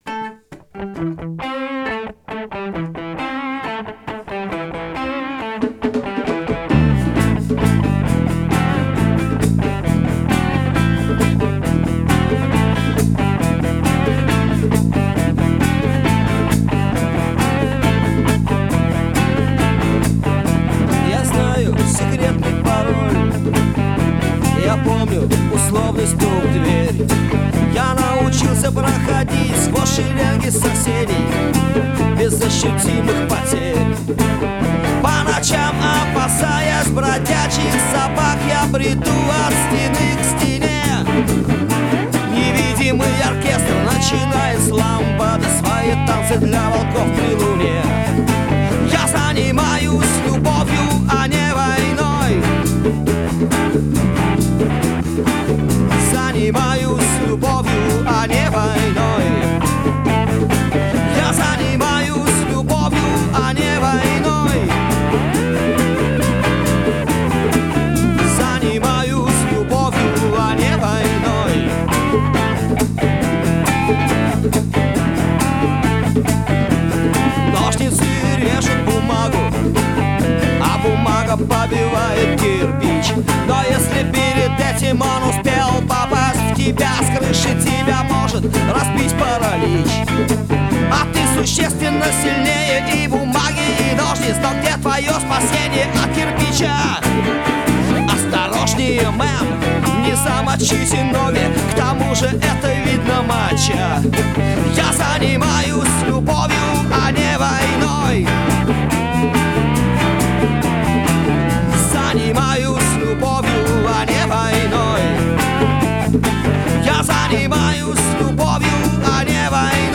Рок музыка Рок Русский рок